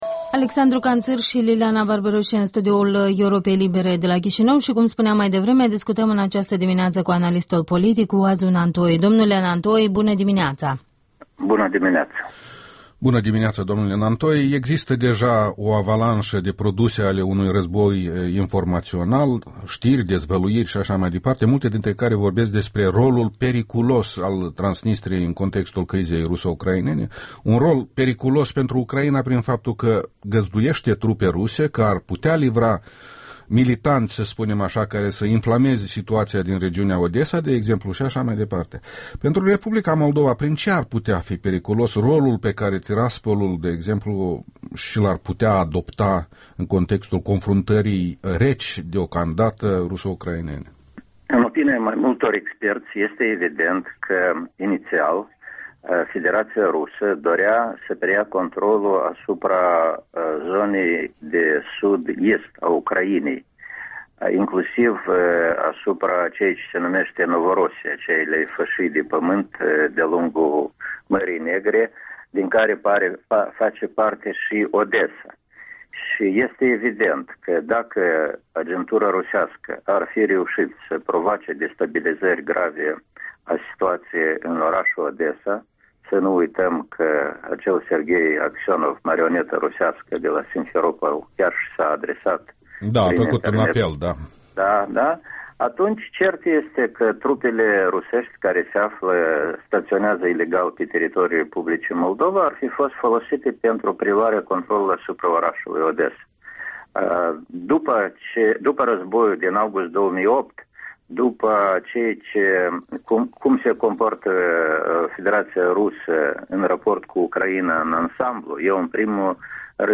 Interviul dimineții cu expertul politic Oazu Nantoi.